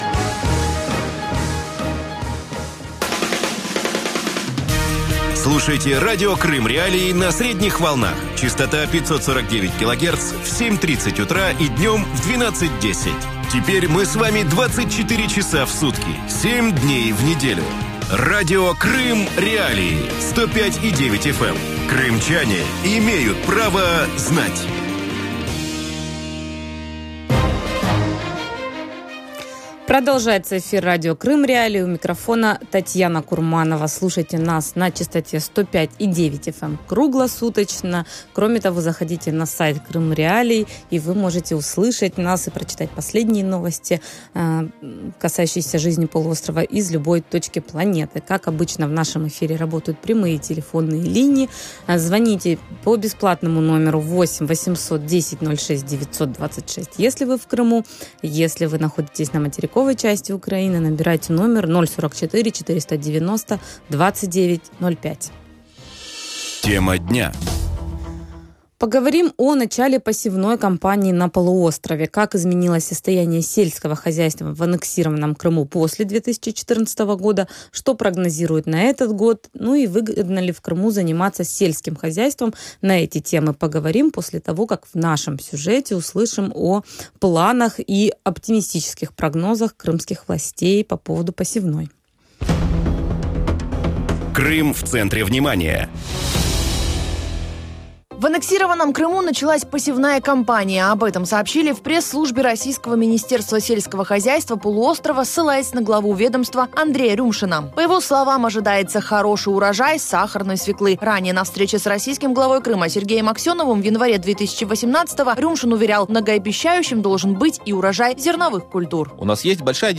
Выгодно ли в Крыму заниматься сельским хозяйством? Ведущая